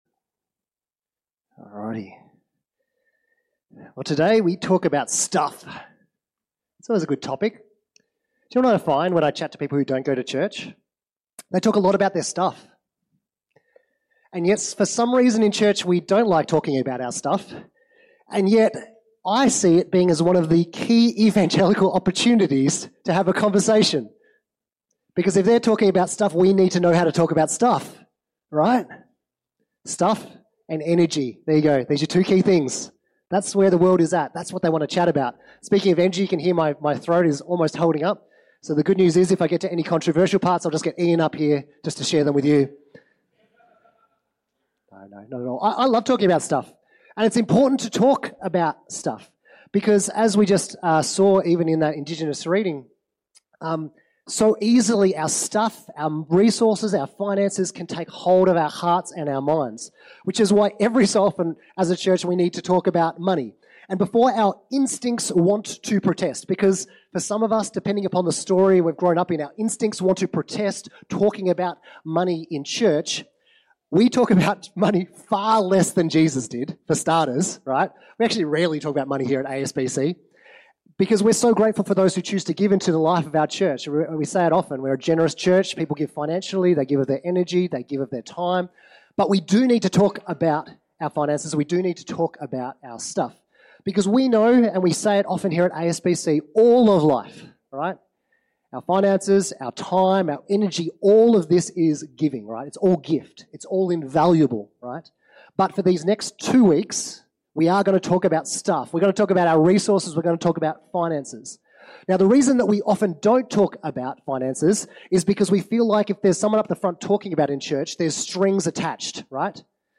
Listen to all the latest sermons from the team at Alice Springs Baptist Church, located in the heart of Australia.